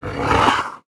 khanat-sounds-sources/sound_library/animals/monsters/mnstr5.wav at f42778c8e2eadc6cdd107af5da90a2cc54fada4c